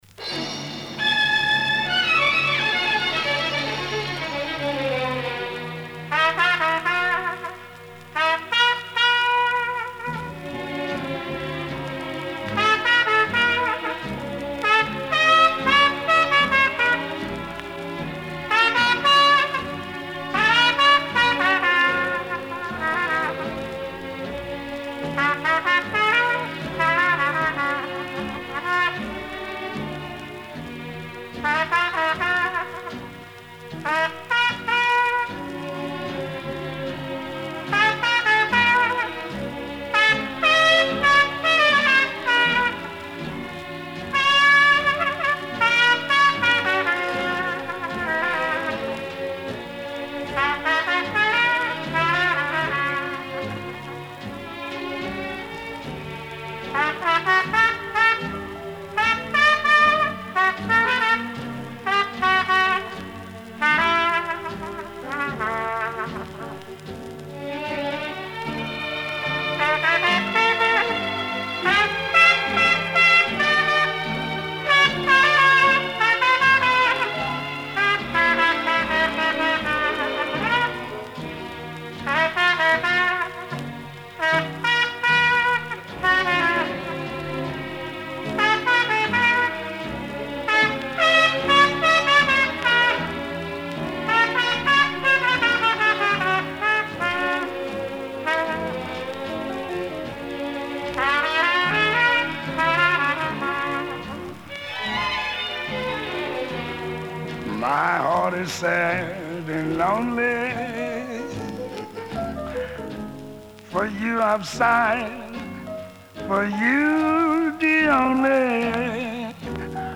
Жанр - Jazz, Стиль: Cool Jazz, Ragtime, Dixieland, Swing.